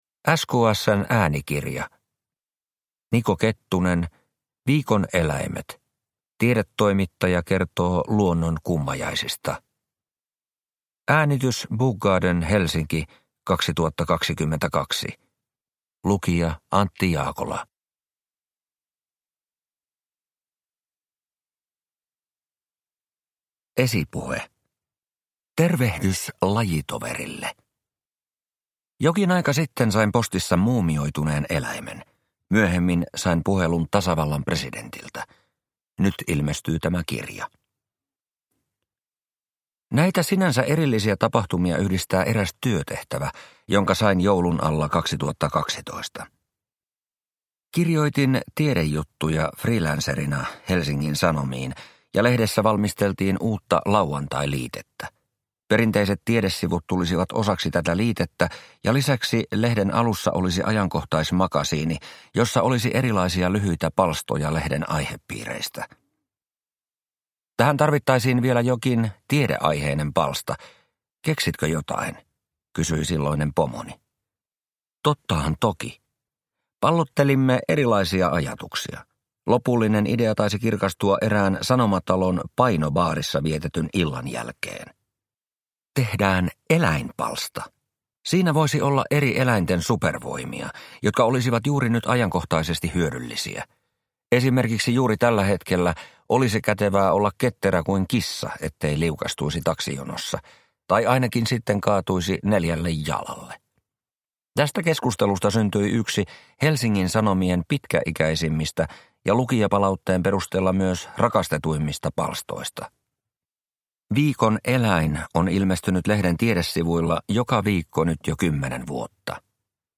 Viikon eläimet – Ljudbok – Laddas ner